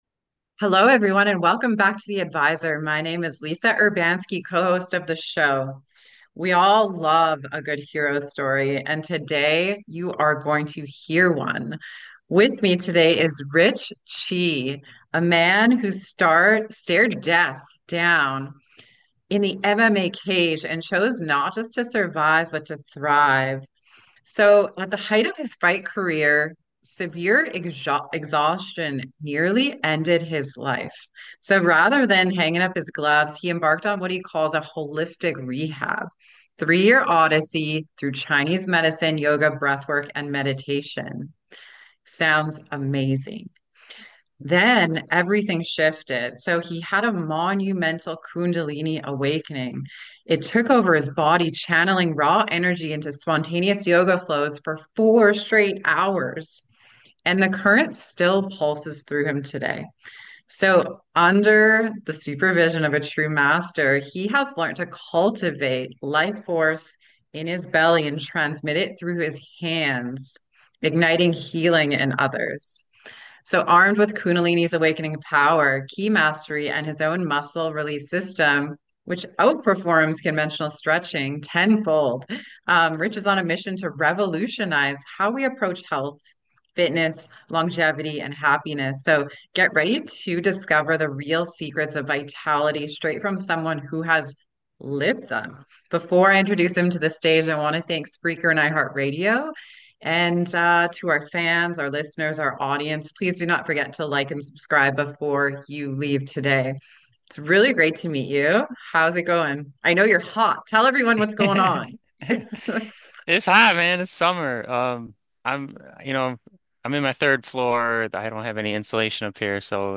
👉 Love insightful and life-changing interviews?